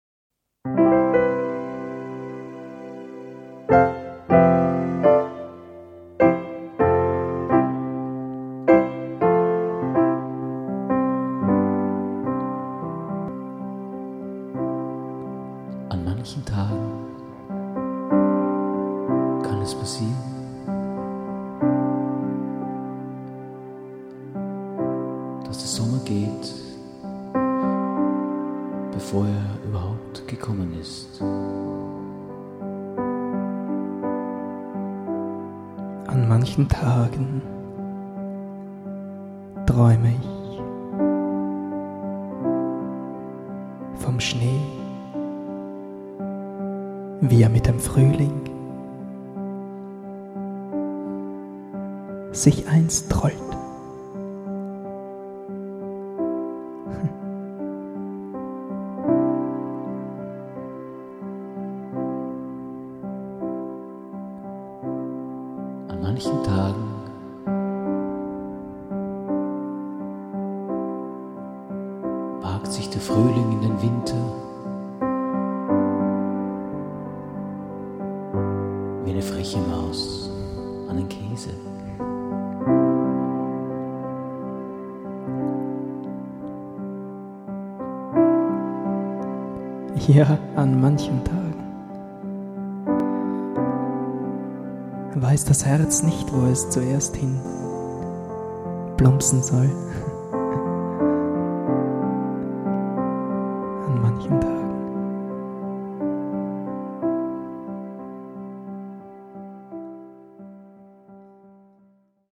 Improvisations-Poesie-Duett